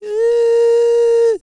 E-CROON 3038.wav